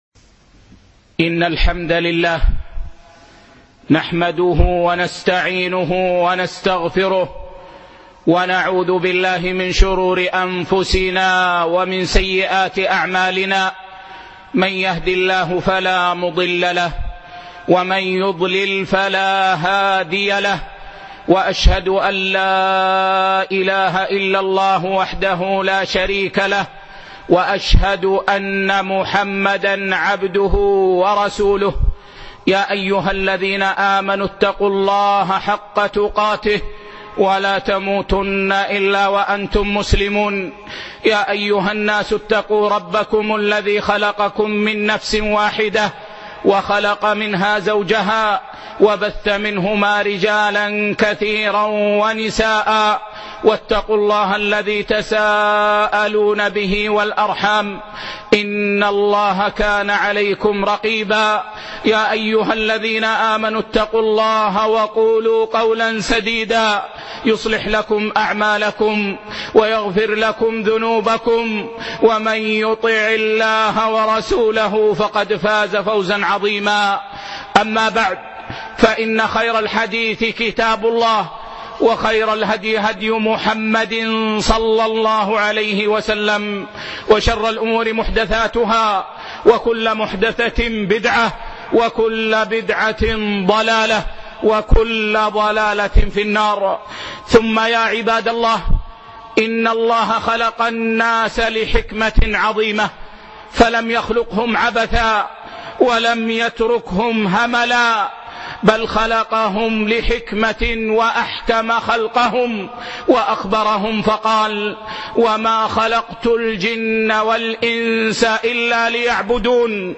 موعظة بليغة - خطبة